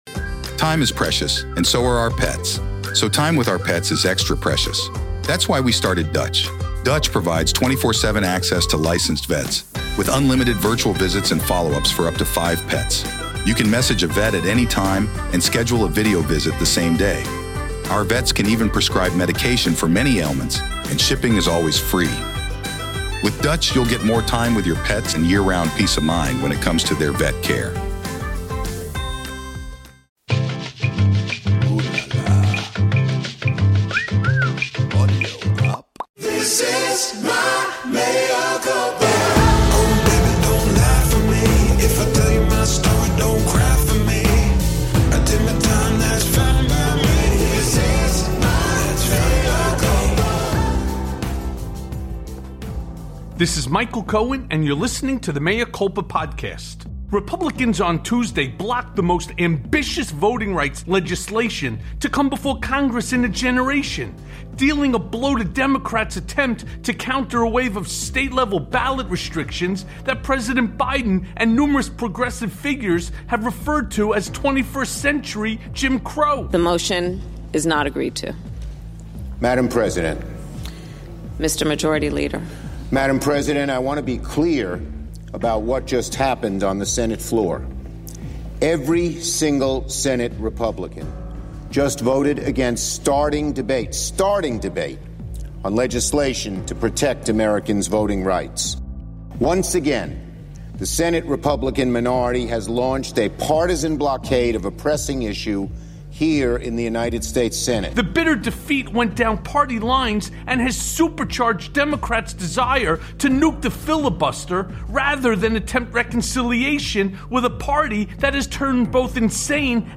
The GOP’s War on Wokeness + A Conversation with Tara Setmayer